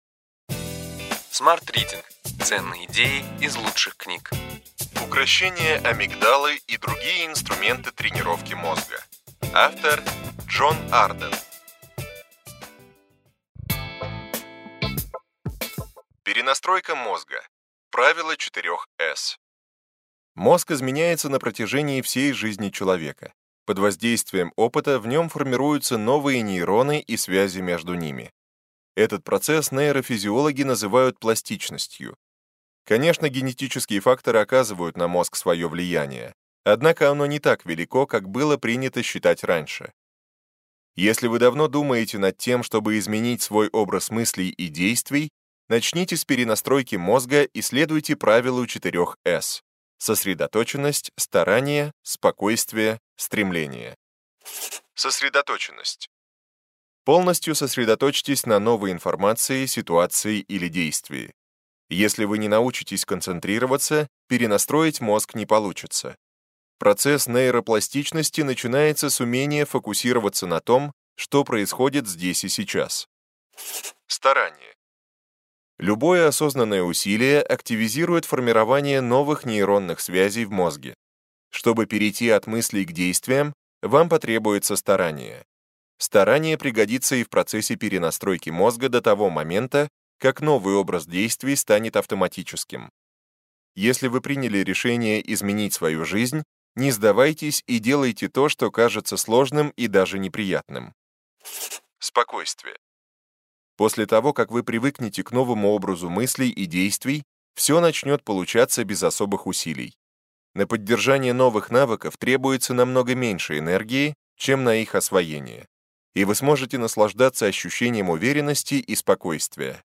Аудиокнига Ключевые идеи книги: Укрощение амигдалы и другие инструменты тренировки мозга.